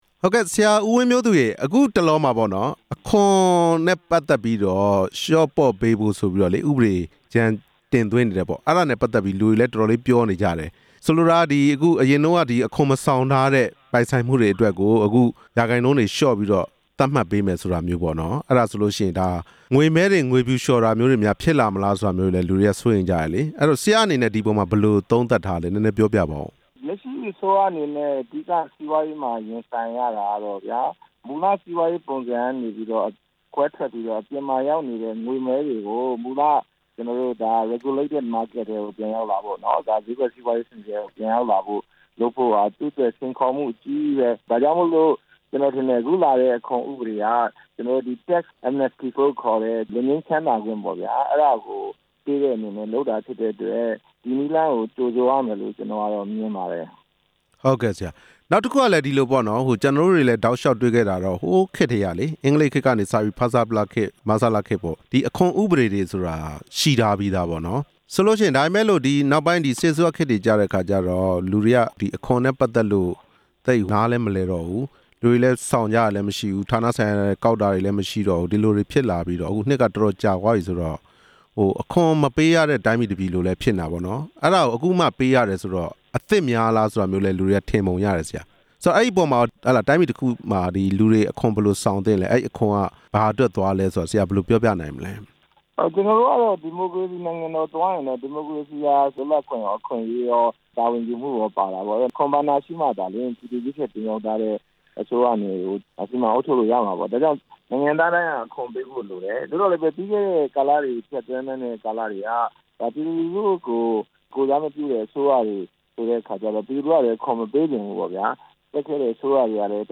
အခွန်ငွေ ပြင်ဆင်မှုနဲ့ ပတ်သက်ပြီး မေးမြန်းချက်